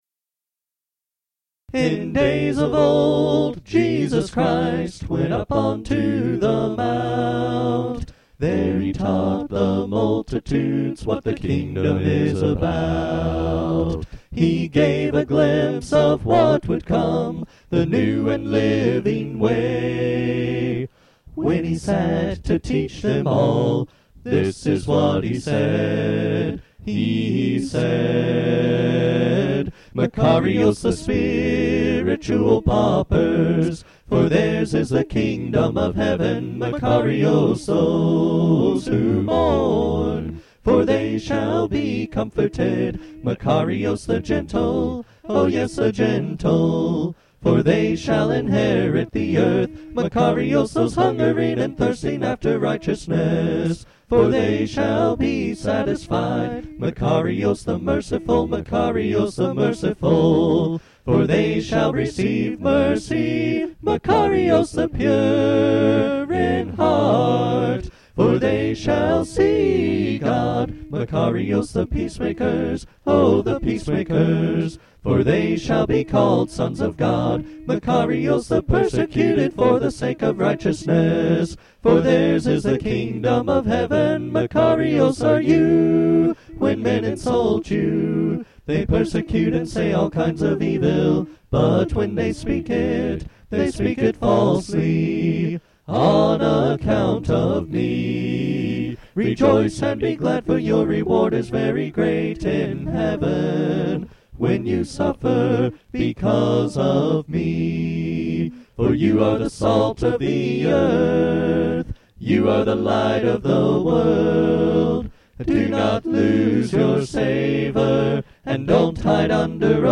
Hear the whole text of the Sermon on the Mount (Matthew 5-7) set to music.